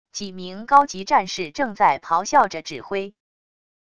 几名高级战士正在咆哮着指挥wav音频